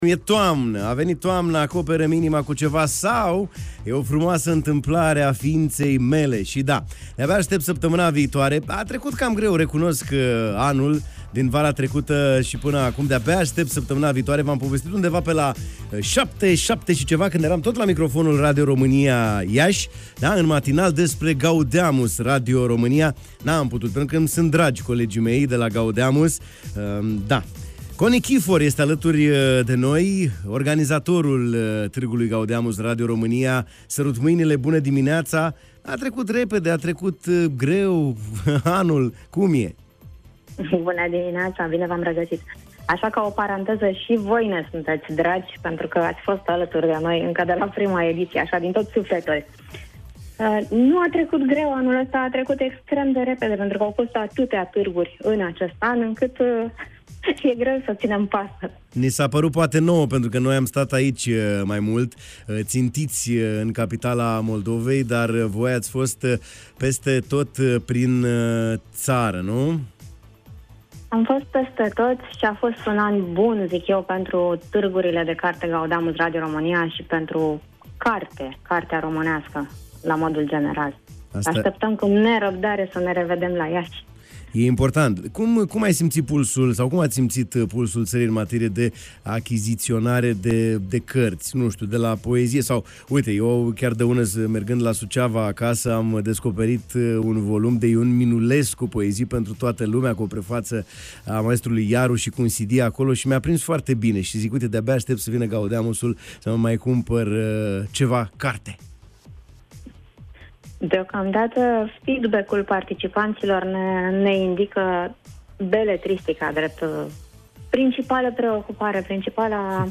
în direct la Radio Iași: